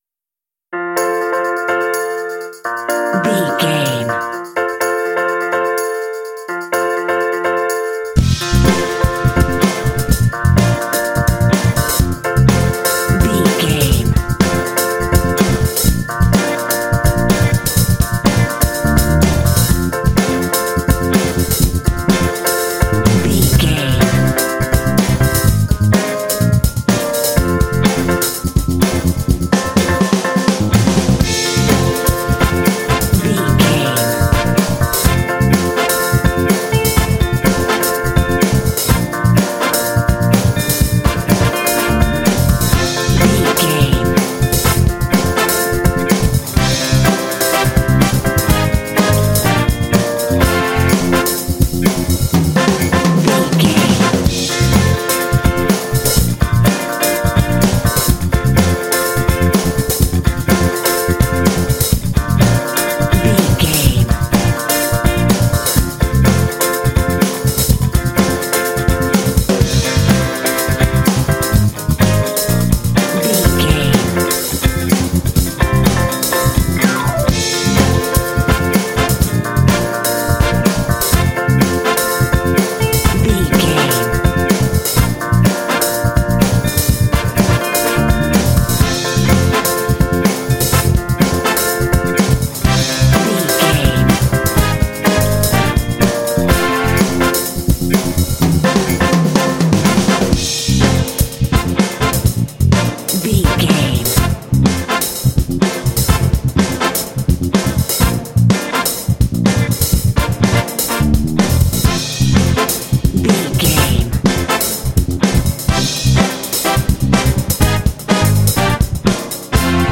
This funk track is reminiscent of 12-bar blues phrasing.
Uplifting
Ionian/Major
groovy
funky
driving
energetic
piano
bass guitar
electric guitar
drums
percussion
electric organ
brass